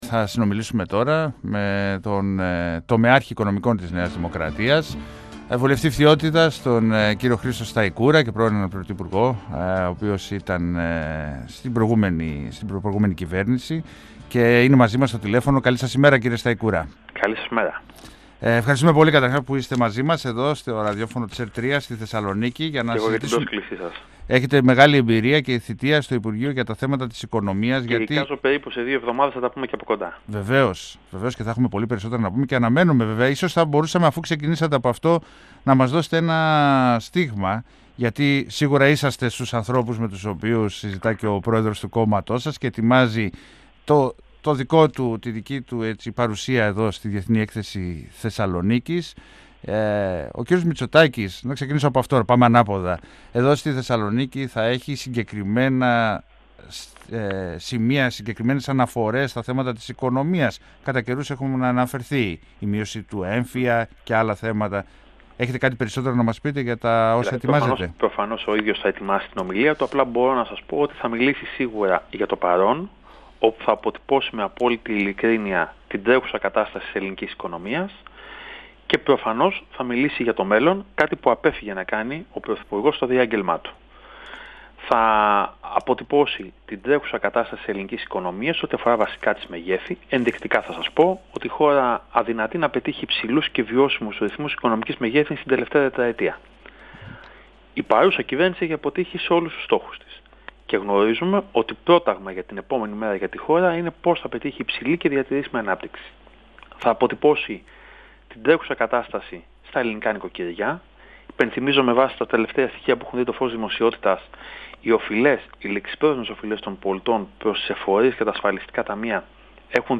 Στην εκπομπή Πολιτικό Ημερολόγιο του 102FM της ΕΡΤ μίλησε ο βουλευτής Φθιώτιδας και τομεάρχης Οικονομικών της ΝΔ Χρήστος Σταϊκούρας